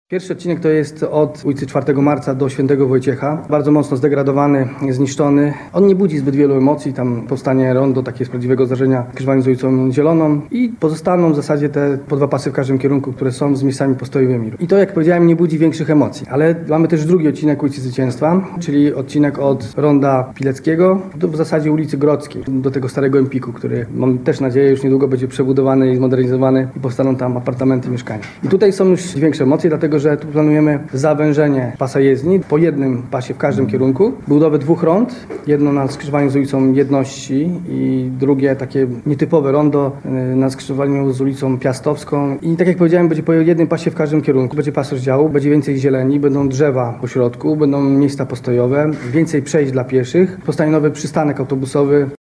Jak zaznacza prezydent miasta, Tomasz Sobieraj, głównym celem modernizacji jest przywrócenie dawnego blasku tej kluczowej arterii w centrum miasta: